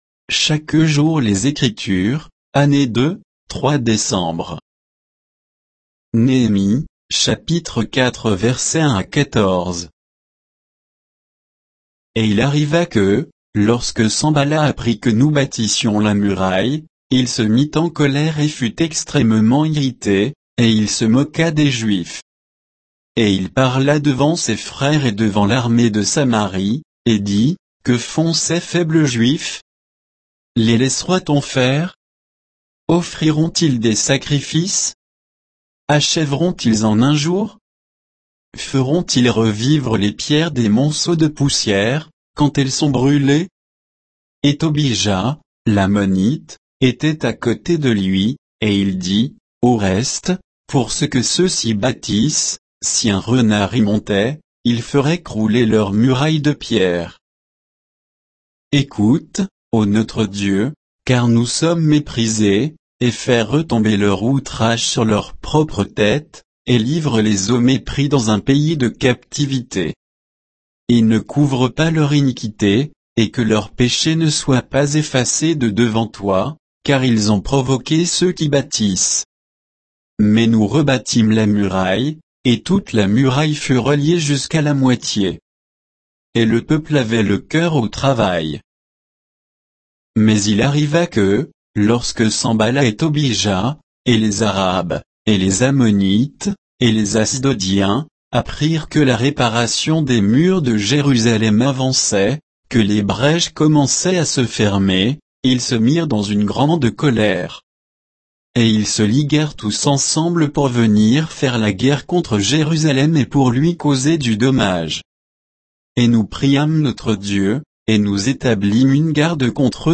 Méditation quoditienne de Chaque jour les Écritures sur Néhémie 4, 1 à 14